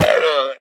1.21.5 / assets / minecraft / sounds / mob / llama / death1.ogg